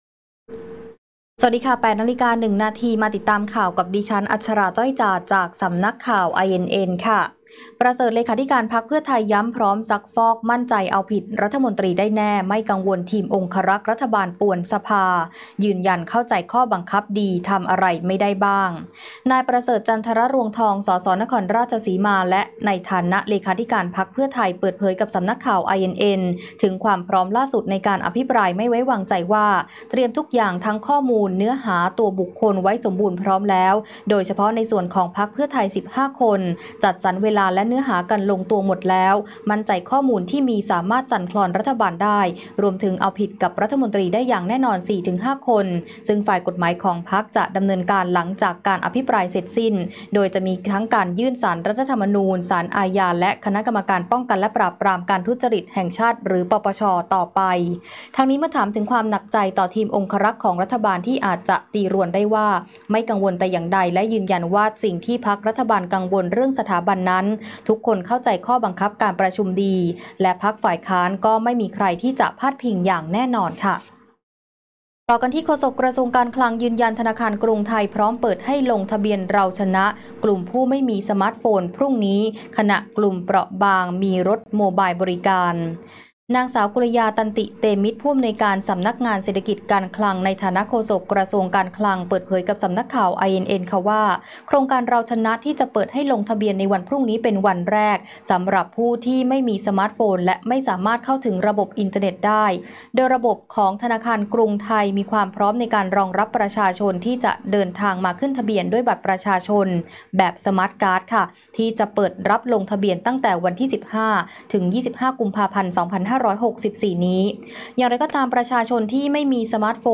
คลิปข่าวต้นชั่วโมง